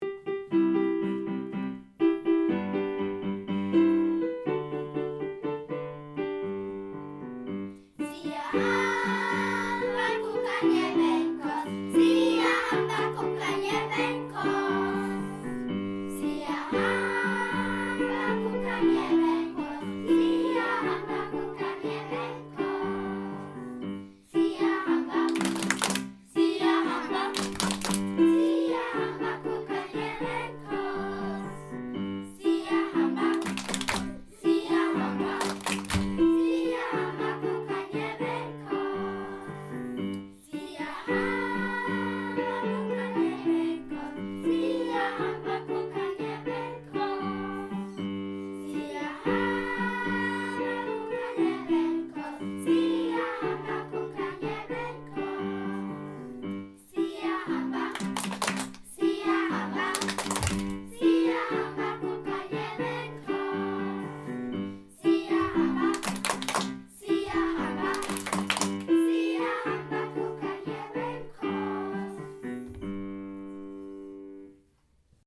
Liedeinspielung (Schulklasse)
siyahamba_voc_kids.mp3